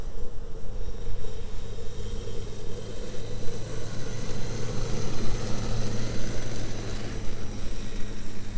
Zero Emission Subjective Noise Event Audio File (WAV)